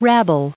1811_rabble.ogg